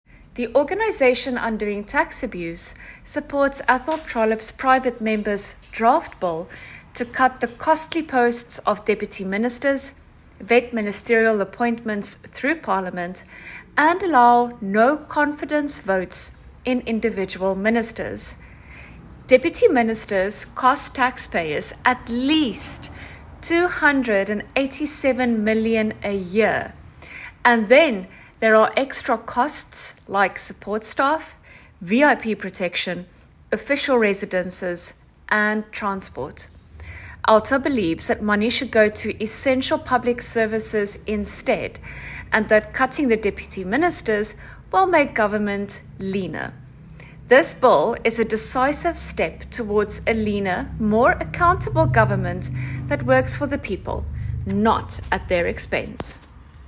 A voicenote with comment